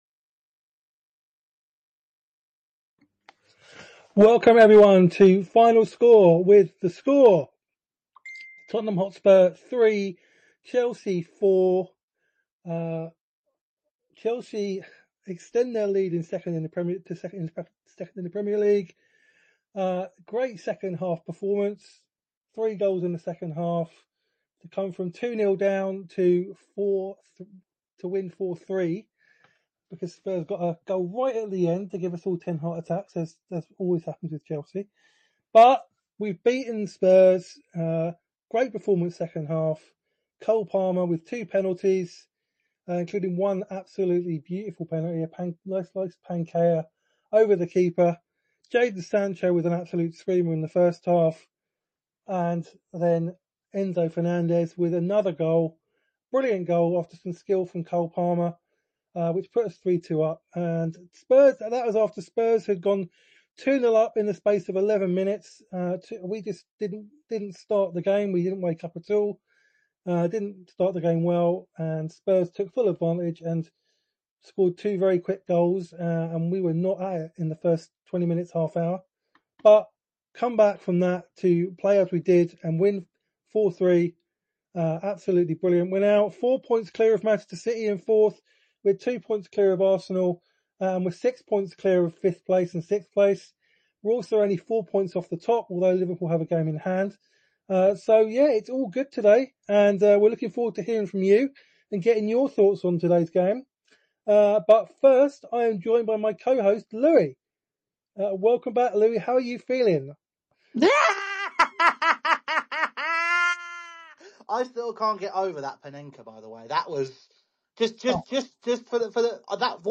ask the important questions whilst taking your calls!